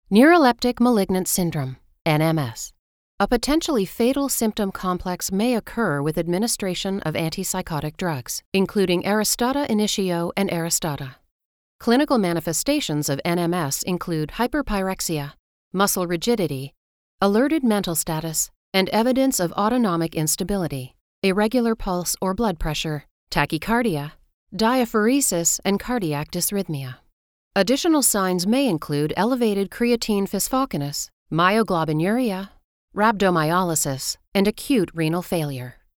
Medical